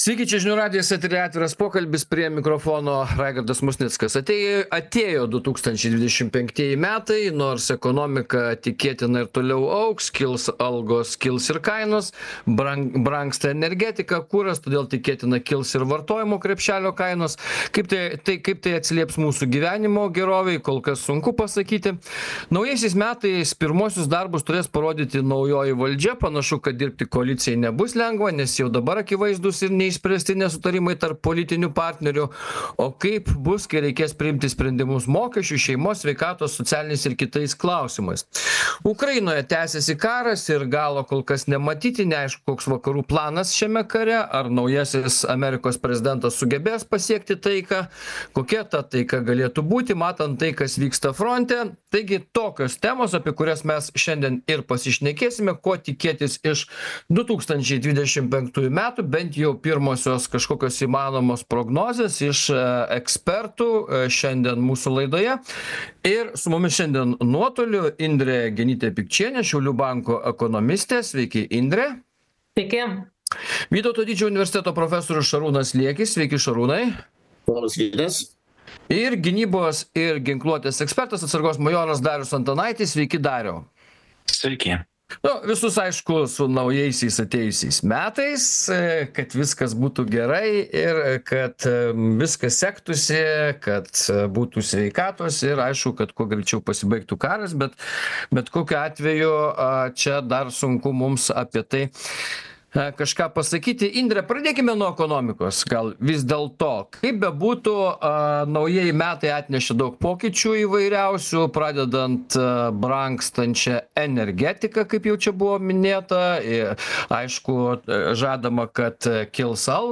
Diskutuoja